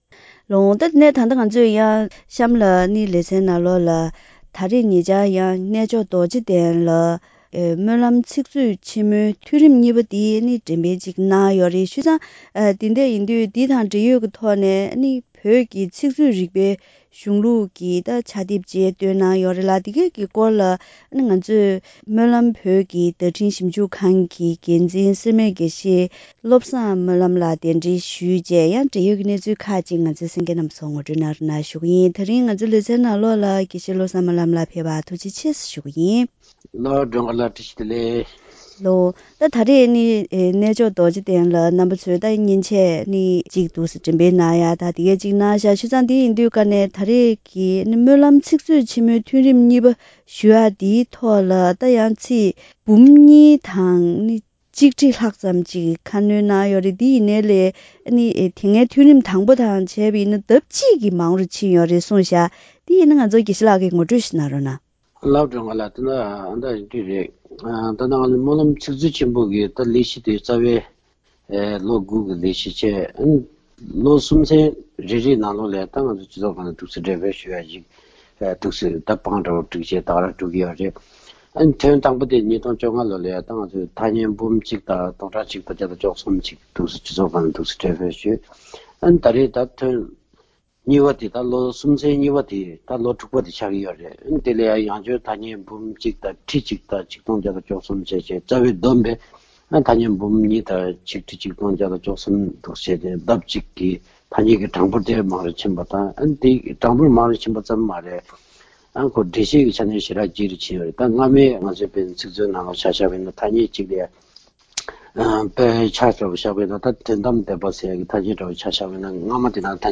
གཏམ་གླེང་ཞལ་པར